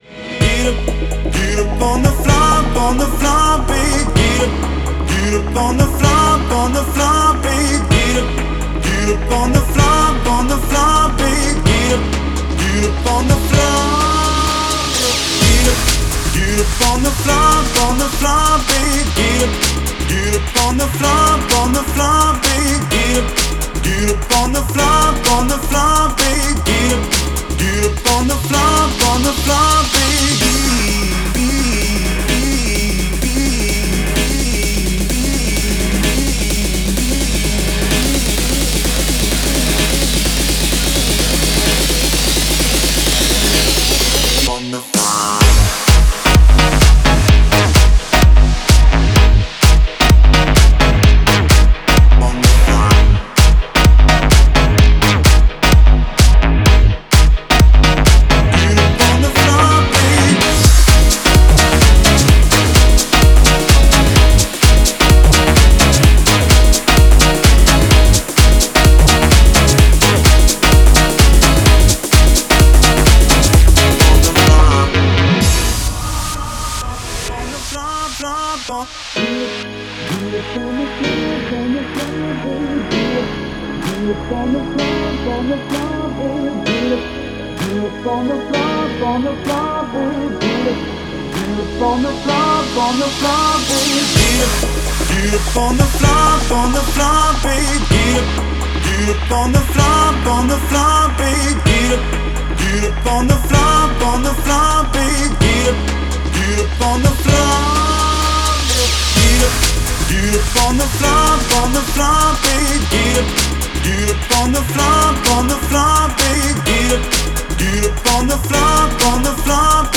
это зажигательная композиция в жанре EDM